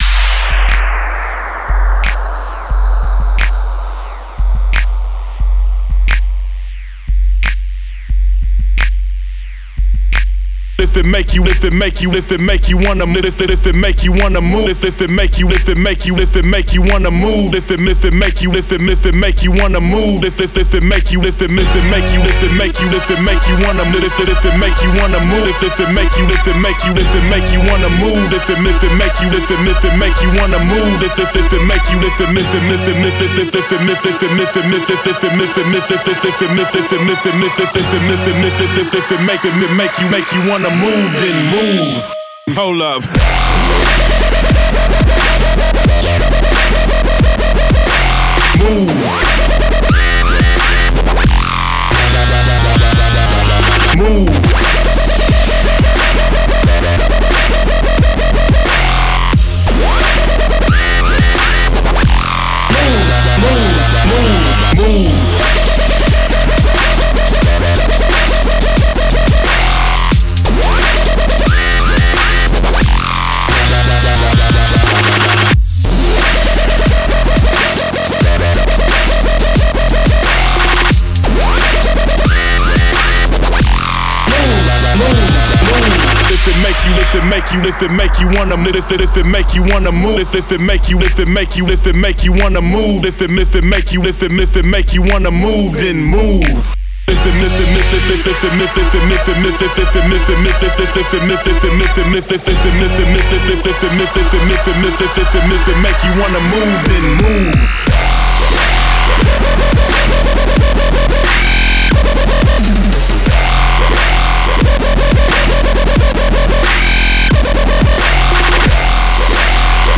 Категория: Dub step